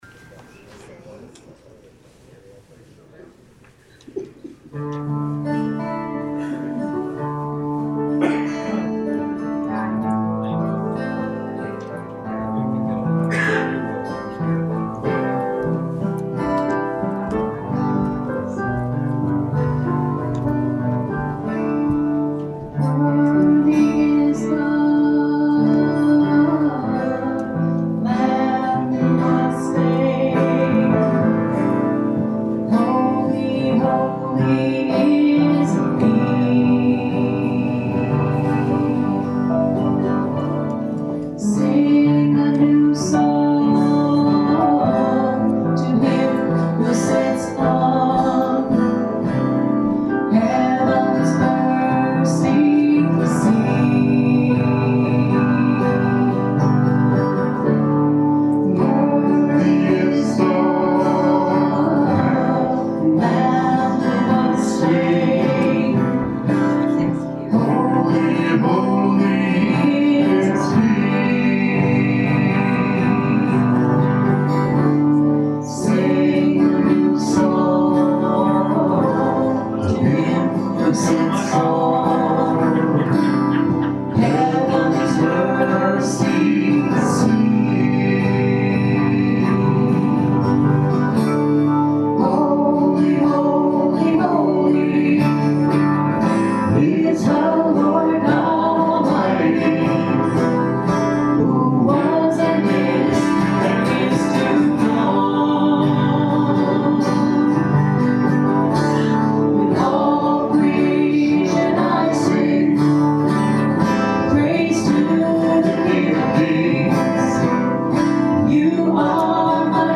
This service was held at the Wales Baptist Church in a joint service with the First Congregational Church of Brimfield.